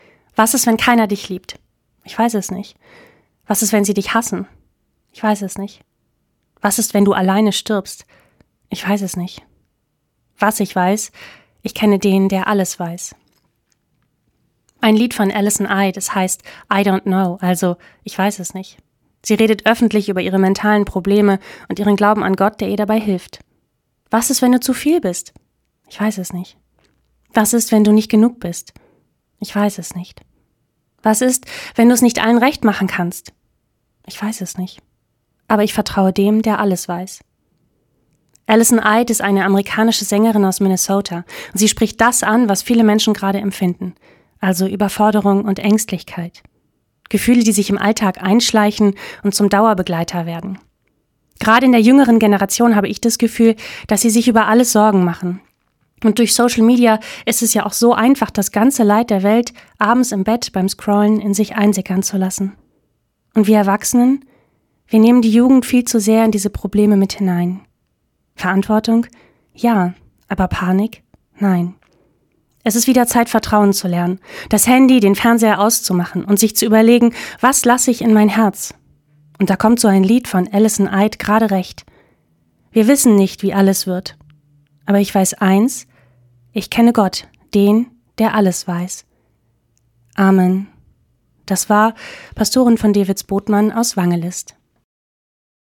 Radioandacht vom 12. September